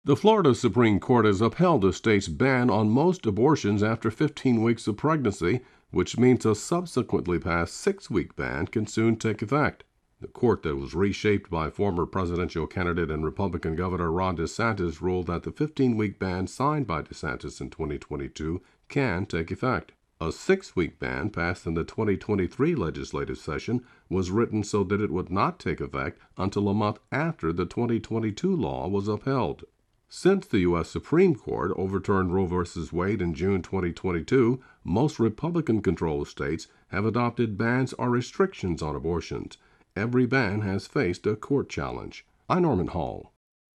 reports on a ruling by the Florida Supreme Court upholding the state’s 15-week ban.